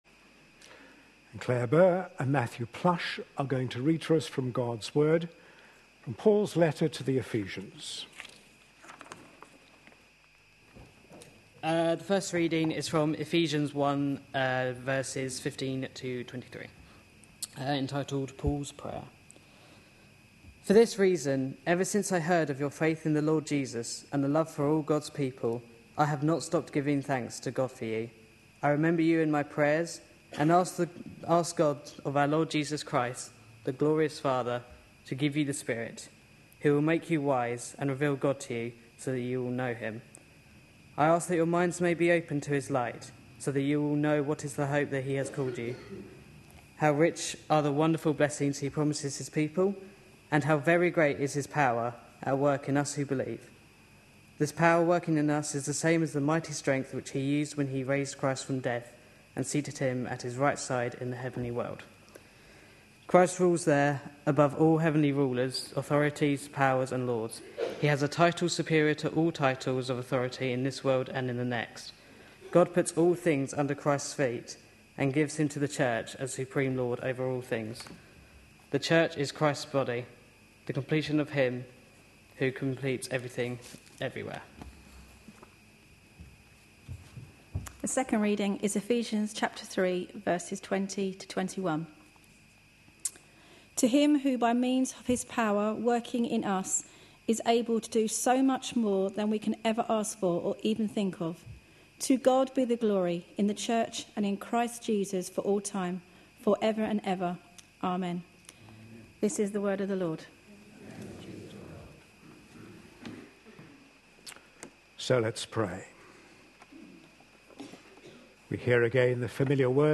A sermon preached on 13th January, 2013.